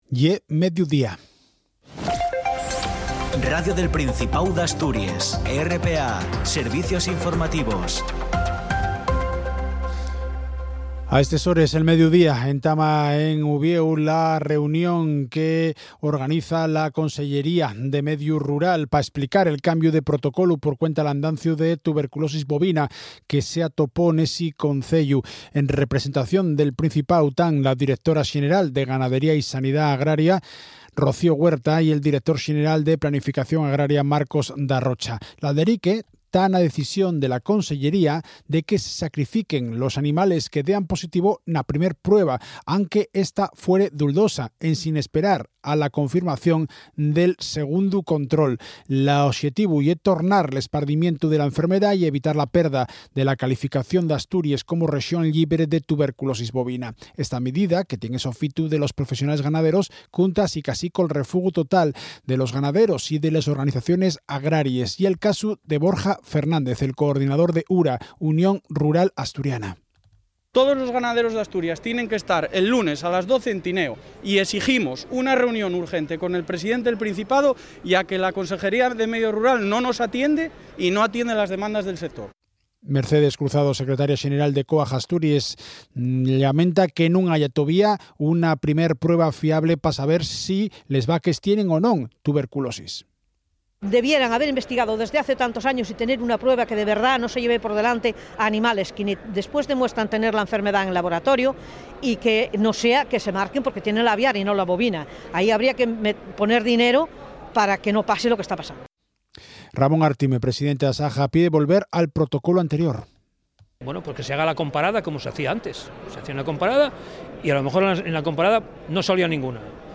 El boletín de las 12:00 horas tiene una duración de 10 minutos y se emite en asturiano. La actualidad general del día en nuestra lengua.